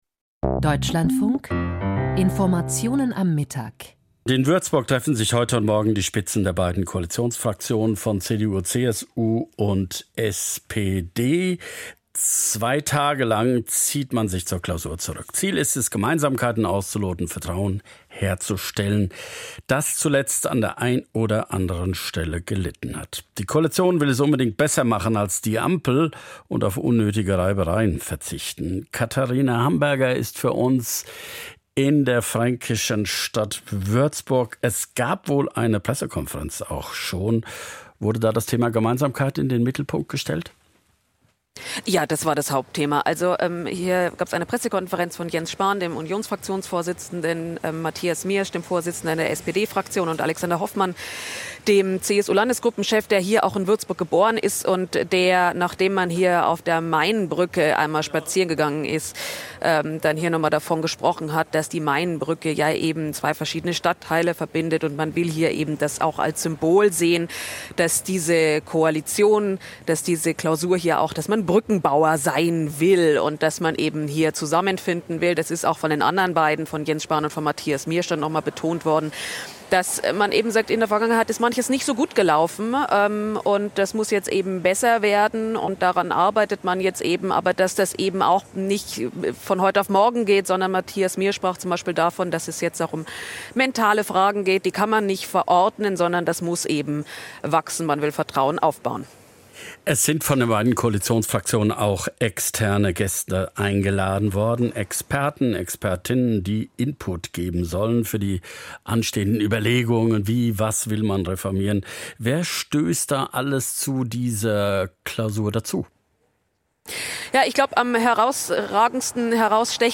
PK zur Koalitionsklausur in Würzburg